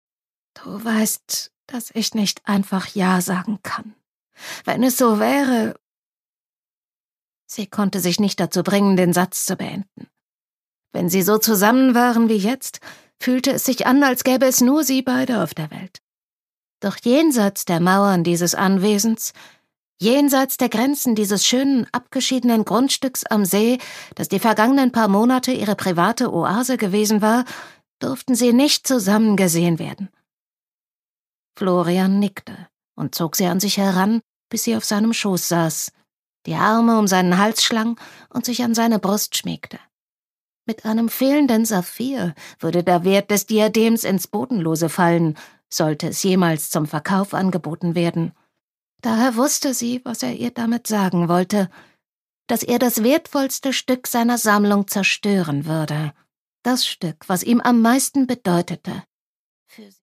MP3 Hörbuch-Download